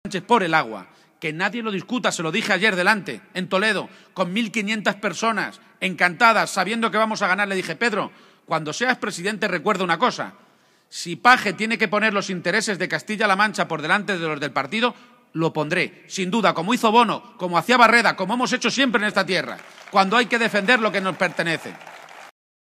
Audio Page en Villarrobledo 2